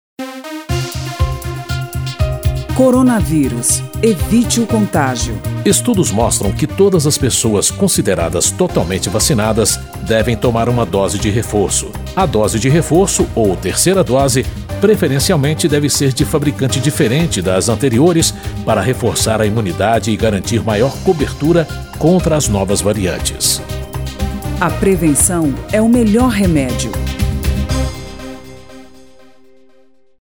spot-vacina-coronavirus-4-1.mp3